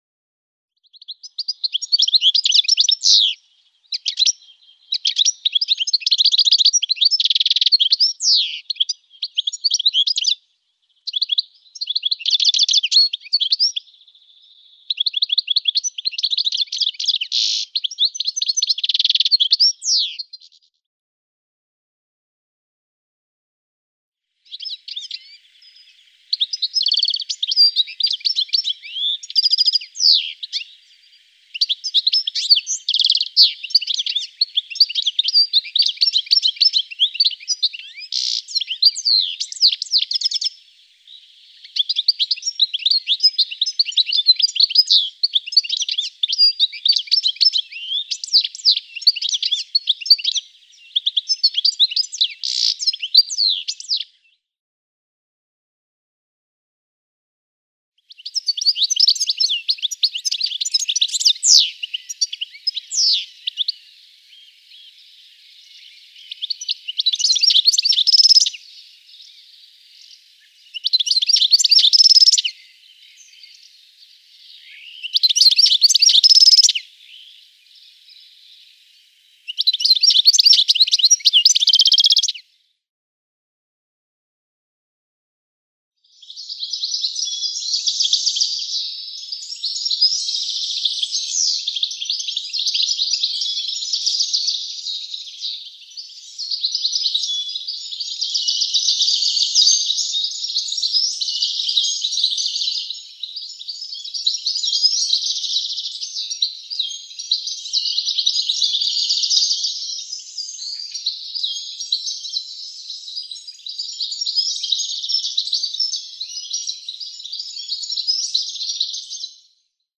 نام فارسی : سهره طلایی نام انگلیسی : European Goldfinch